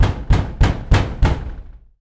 doorhammer.ogg